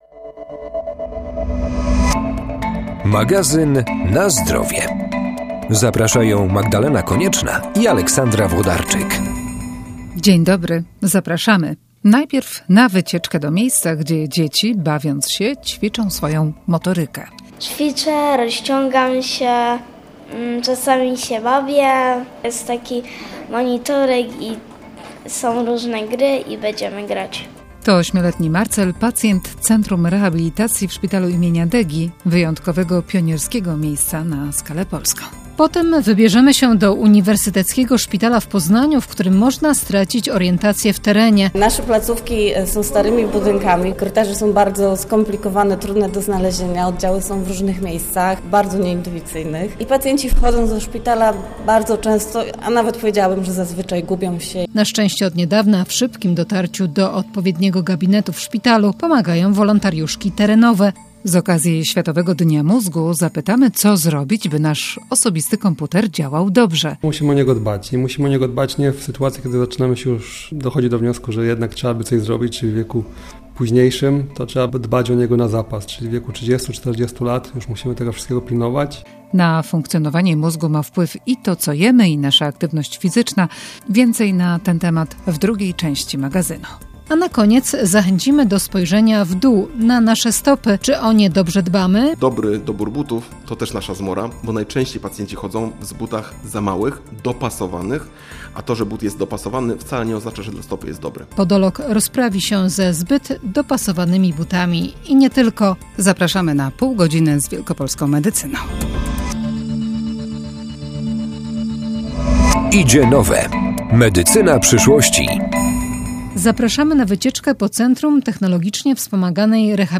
W tym tygodniu przypadał Światowy Dzień Mózgu - zapytaliśmy specjalisty, jak dbać o ten ludzki komputer. A na koniec inny specjalista - podolog - opowie jak dbać o stopy.